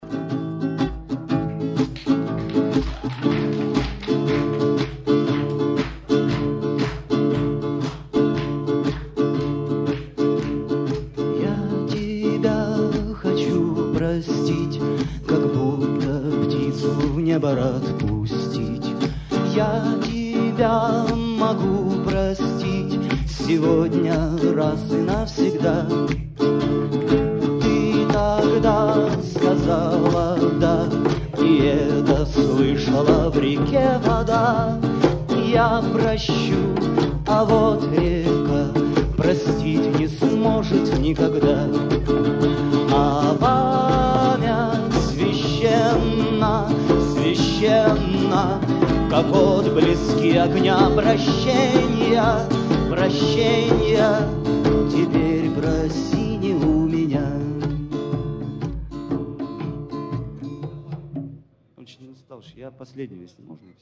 * в плохом качестве и только минута